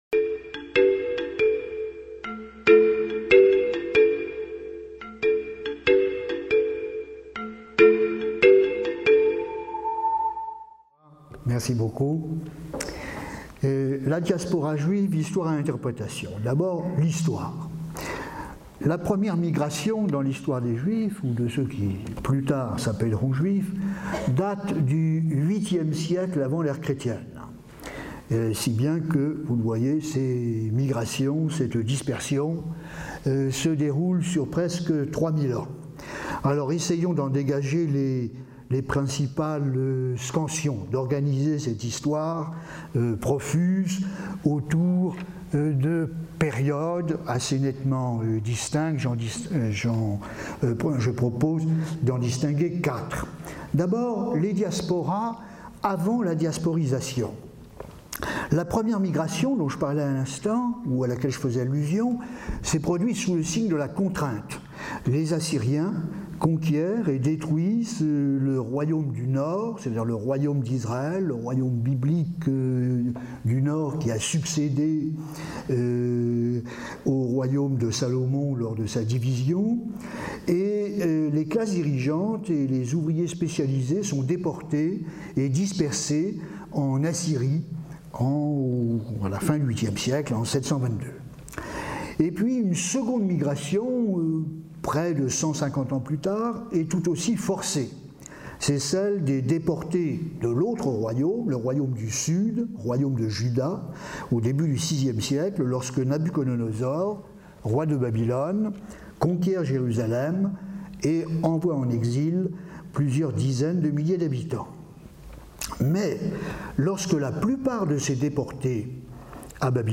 La conférence se fixera un double objectif.